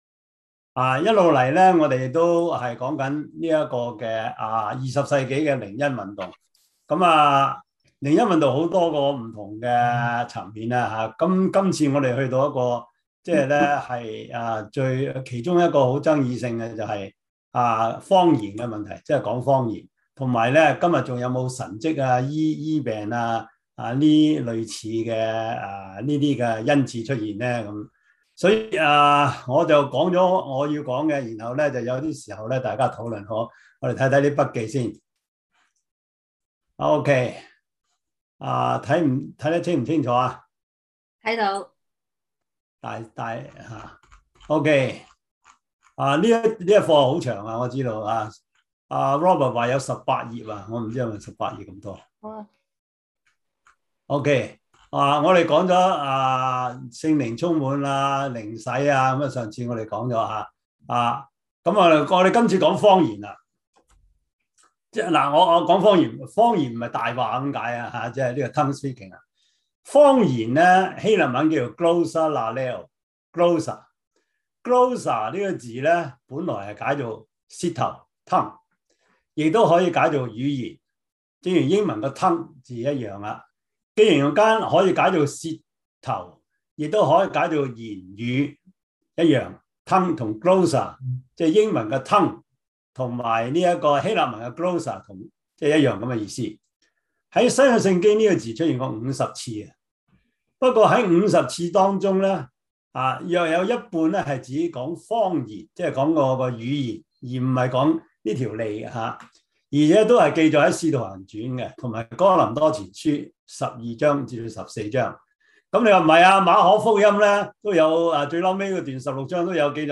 中文主日學